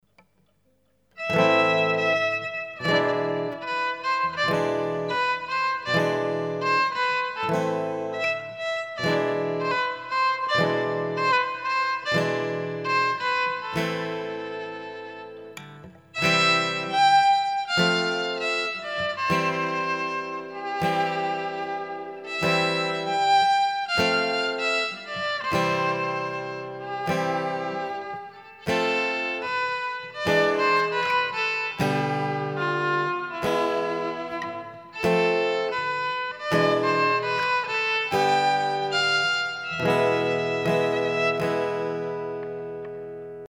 VIOLON FOLK